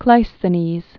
(klīsthə-nēz) or Clis·the·nes (klĭs-) fl. sixth century BC.